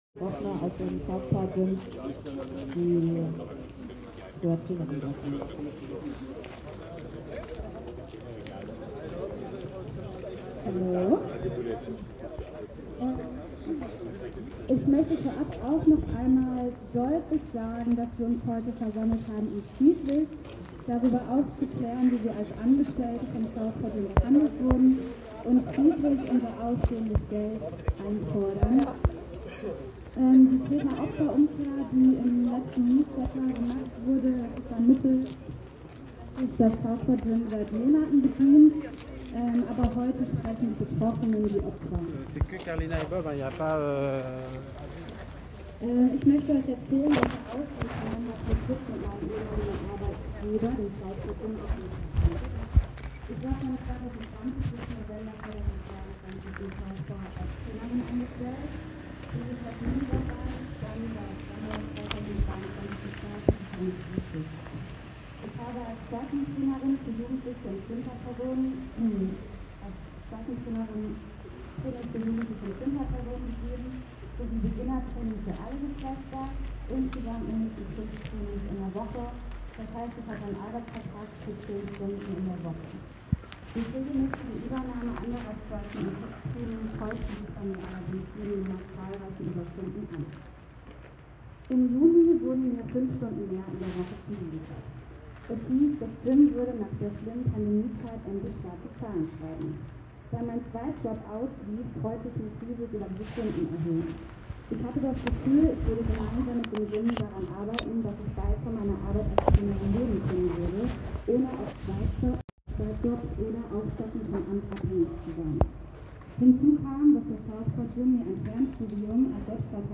Kundgebung vor Kampfsportstudio
Etwa 100 Personen versammelten sich am Abend vor einem Kampfsportstudio in Connewitz.
Der Redebeitrag einer betroffenen Trainerin: